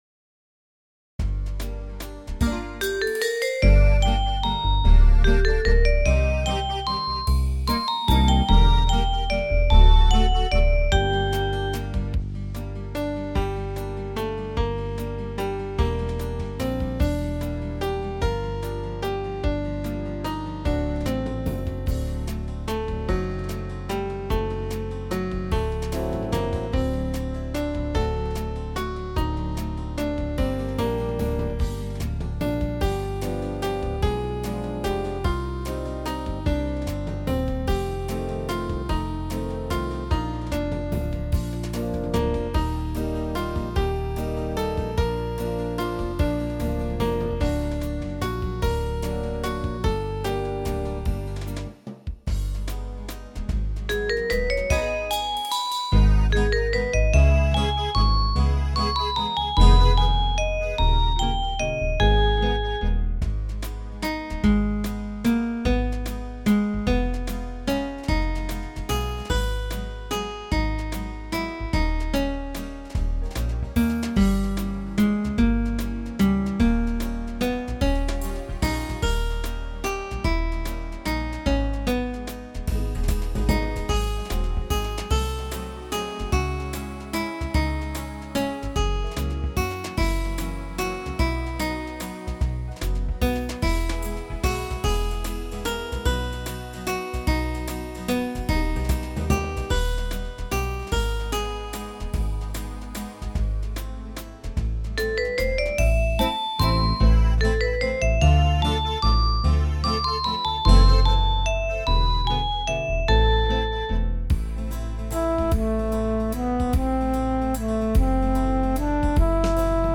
Рубрика: Поезія, Авторська пісня
І мелодія така чуттєва.
Чудовий вальс осінніх квітів give_rose give_rose give_rose